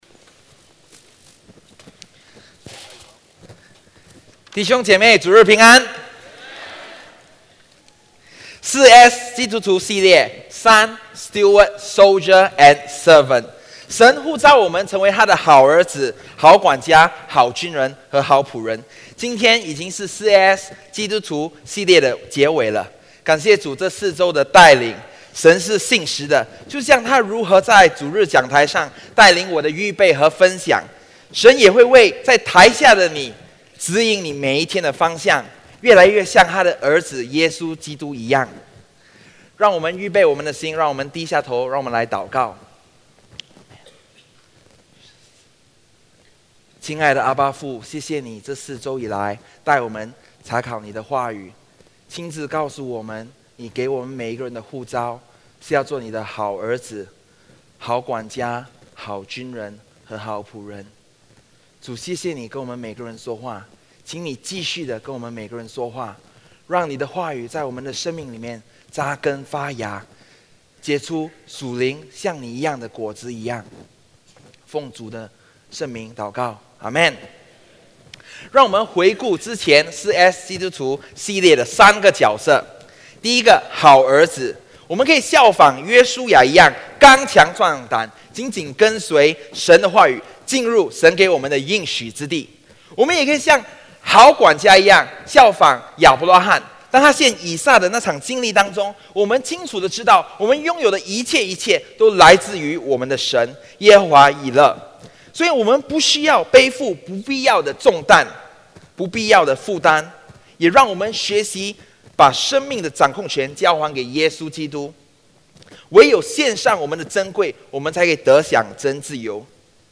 主日证道